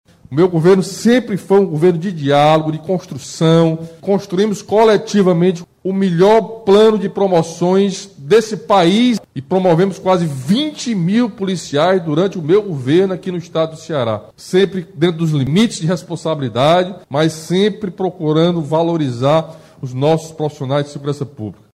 Em transmissão ao vivo via redes sociais na manhã desta quarta-feira (18), o governador do Ceará, Camilo Santana anunciou novos concursos públicos para a área da Segurança Pública do Ceará, com um total de 3.128 vagas, sendo 2.200 para Polícia Militar, 500 para a Polícia Civil, 170 para a Perícia Forense (Pefoce), e também autorizou o chamamento dos 258 aprovados nos últimos concursos do Corpo de Bombeiros.